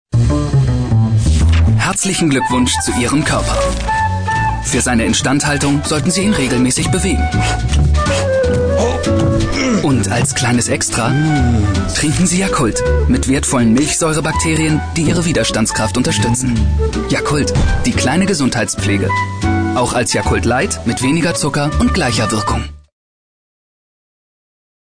deutscher Sprecher, Dialekt: norddeutsches Platt
Sprechprobe: Industrie (Muttersprache):
german voice over artist